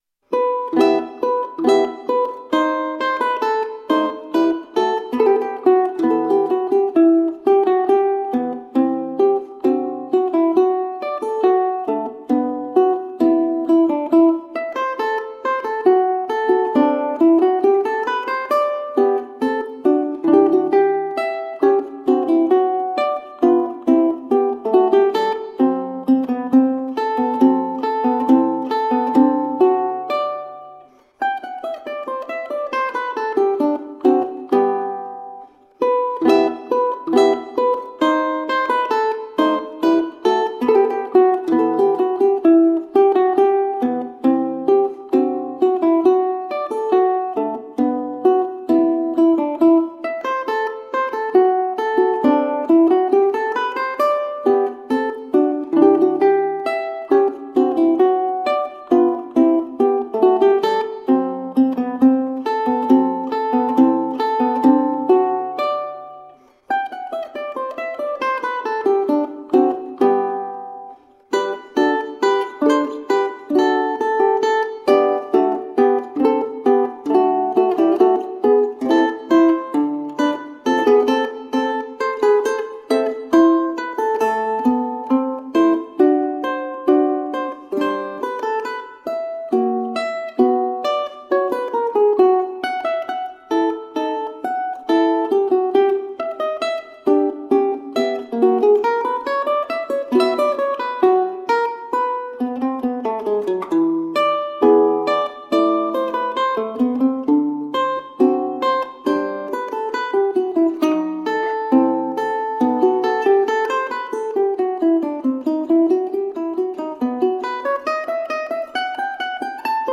Classical, Baroque, Instrumental